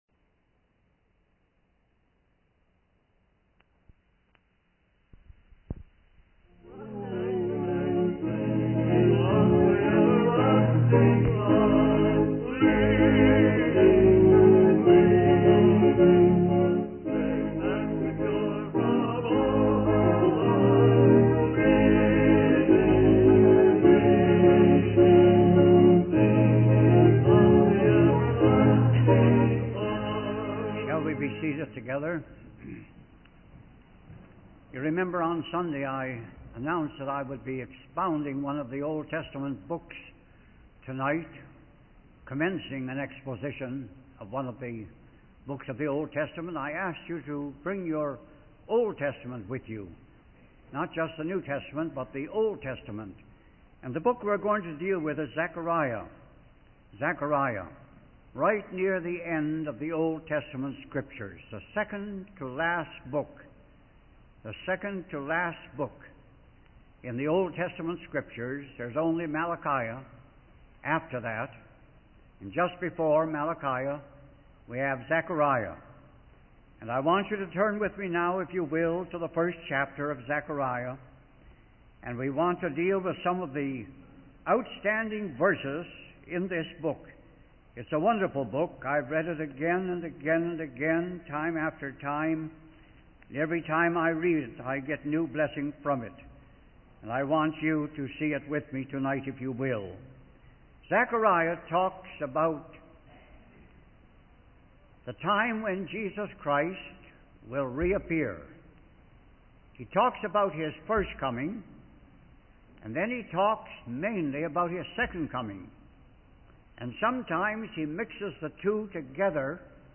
In this sermon, the preacher focuses on the fourth chapter of a book, possibly from the Bible.